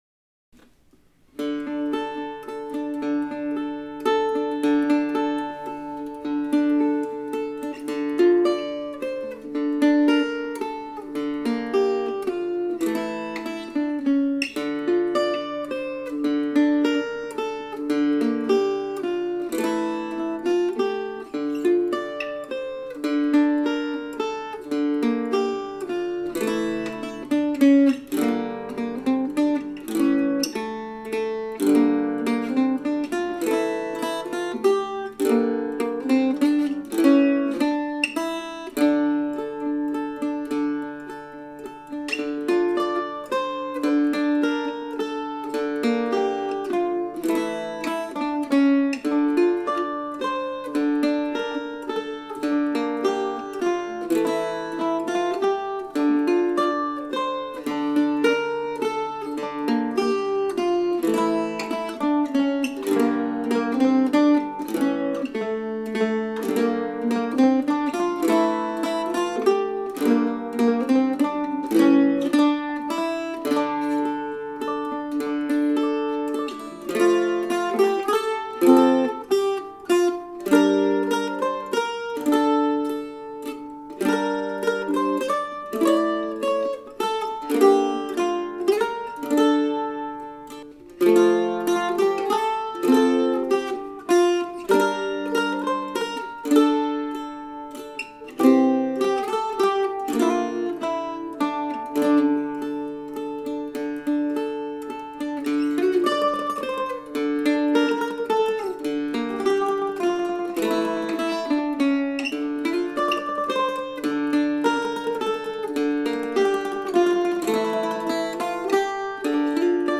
Today's tune was written yesterday on the new octave mandolin and recorded using it along with a fine old Gibson mandolin. I imagined that the snow had fallen and I was finished with the shoveling, looking out my window at the fresh powder.